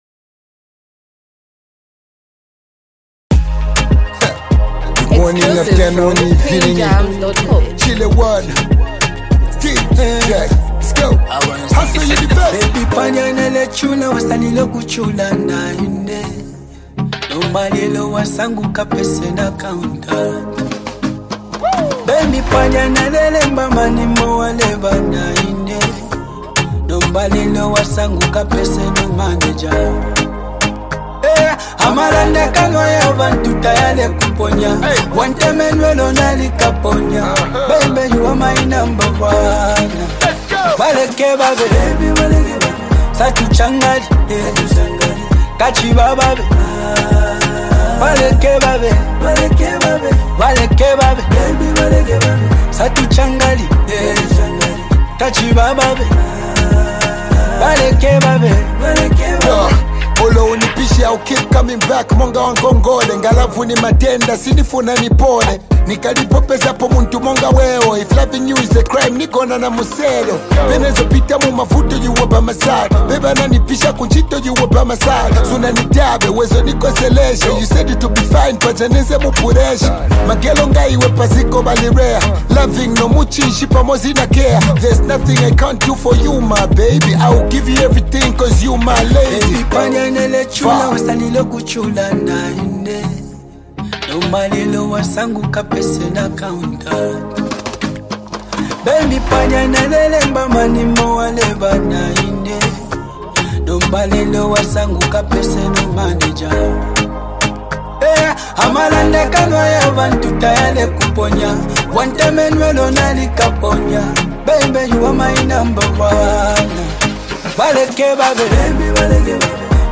soulful and emotional delivery adds depth to the song